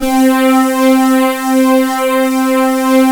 GREAT PAD C5.wav